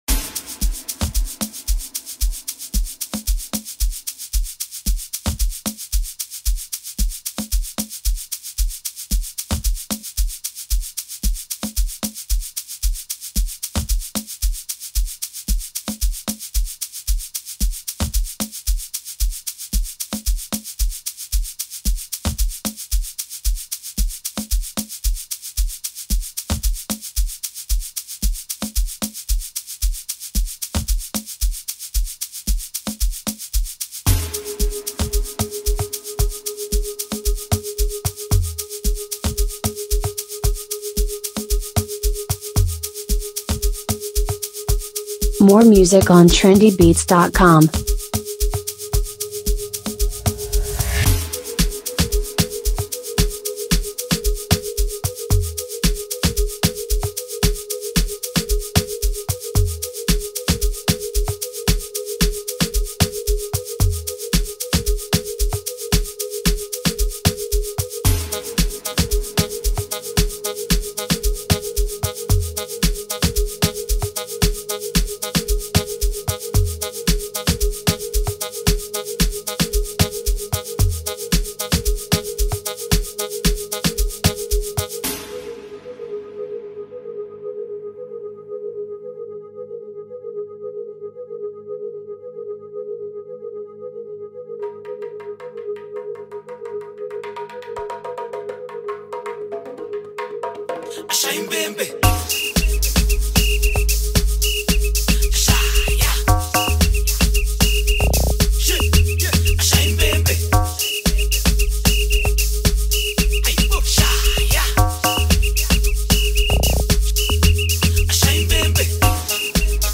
Amapiano sound